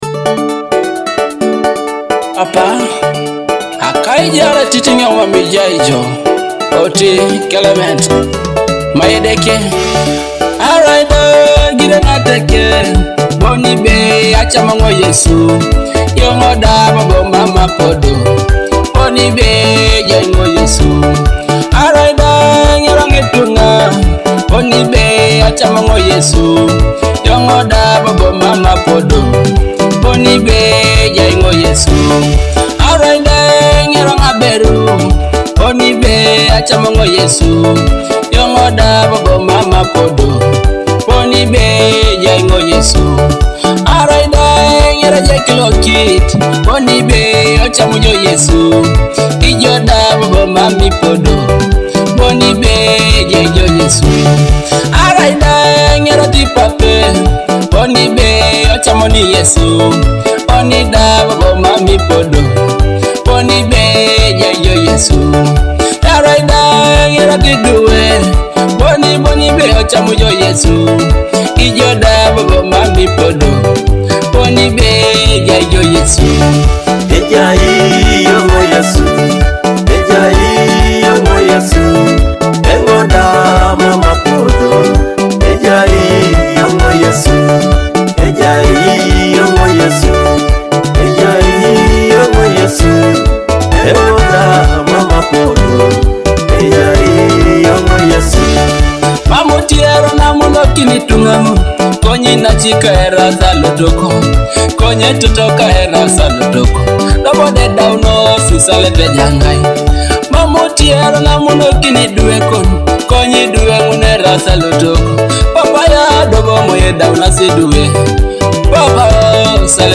a powerful Teso gospel song meaning “Jesus is with me
uplifting Teso gospel track
soulful vocals
traditional Teso rhythms